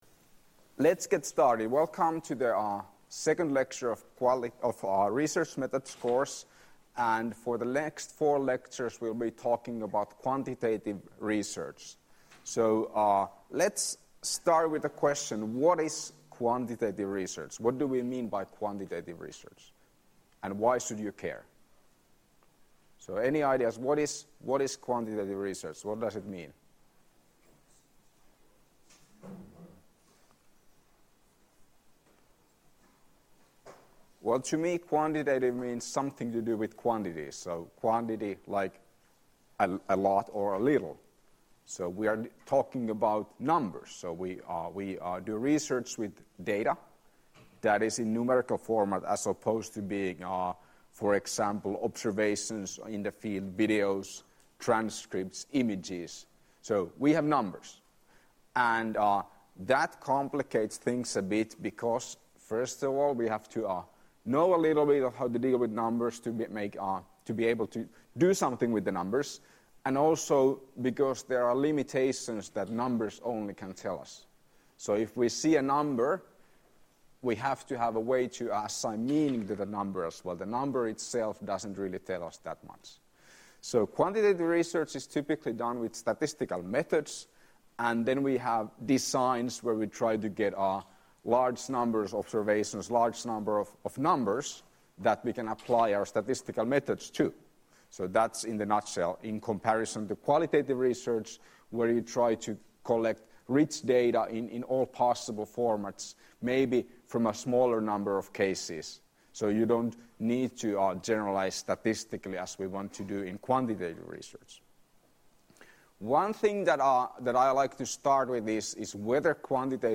Lecture 8.9.2016 — Moniviestin